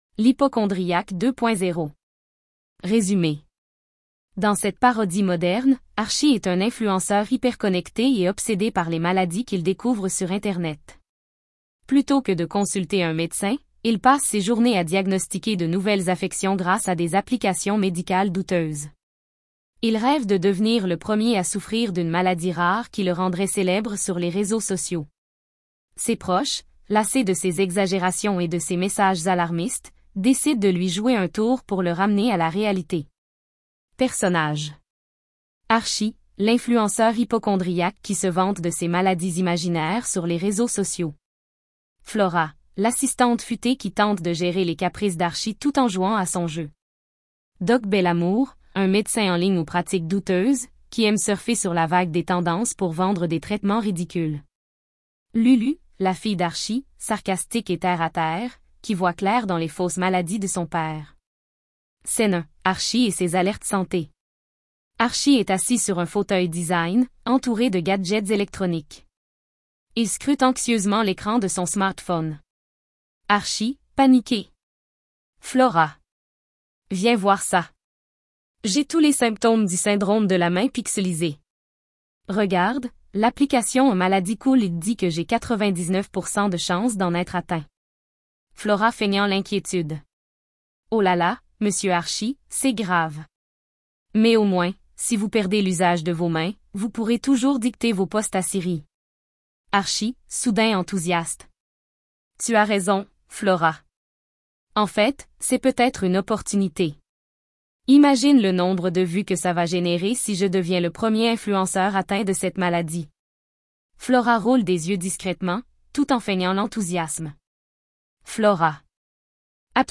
Merci IA